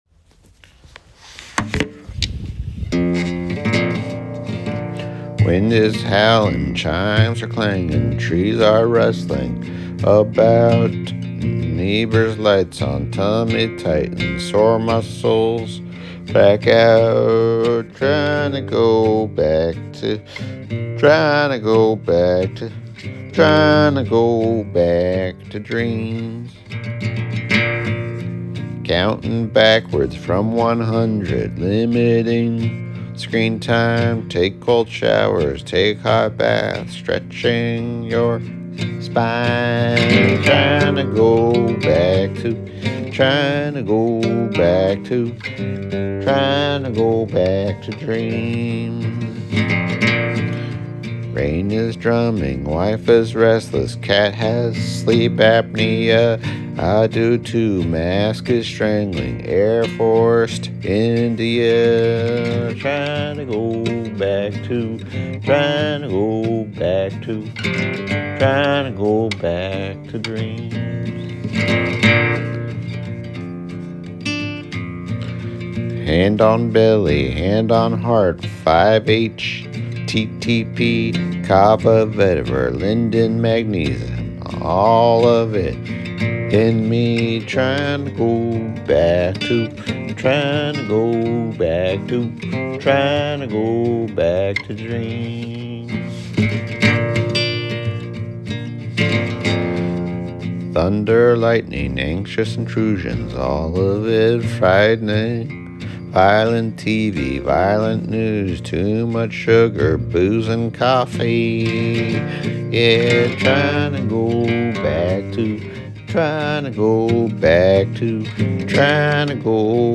I like your friendly strumming and voice.
Great melody!